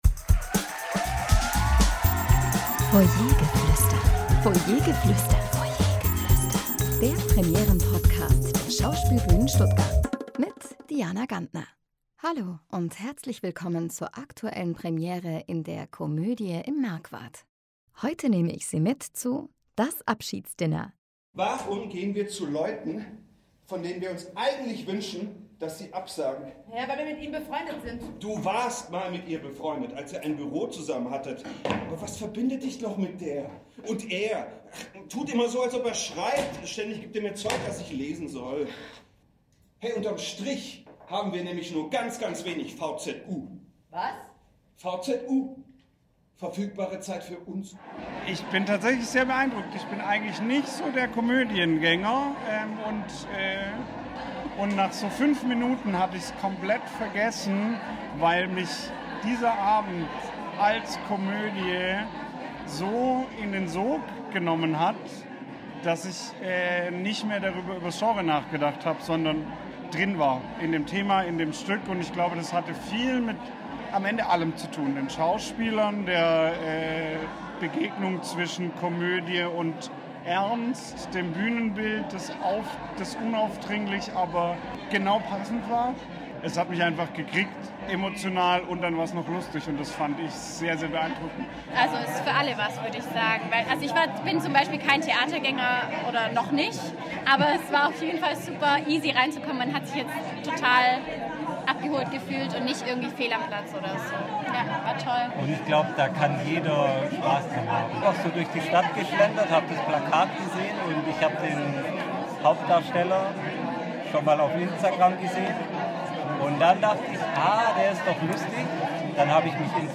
Zuschauerstimmen zur Premiere von “Das Abschiedsdinner”